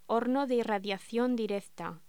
Locución: Horno de irradiación directa